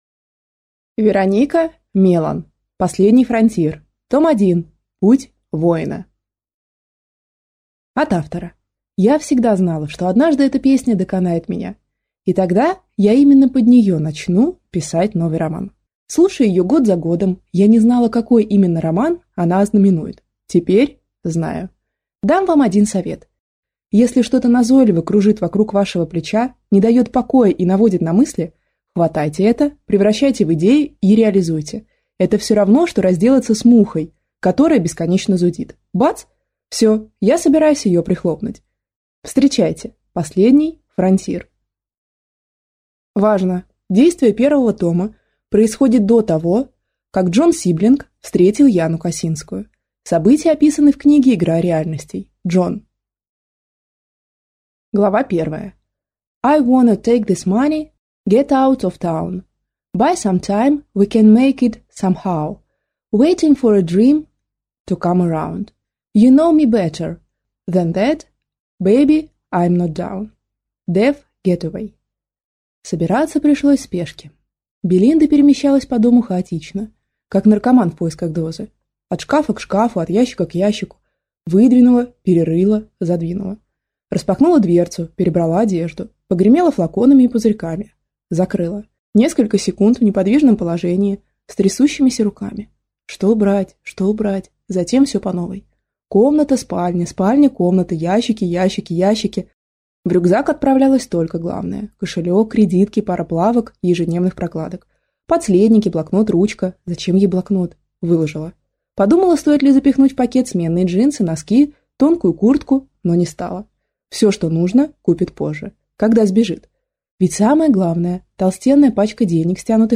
Аудиокнига Последний Фронтир. Том 1. Путь Воина - купить, скачать и слушать онлайн | КнигоПоиск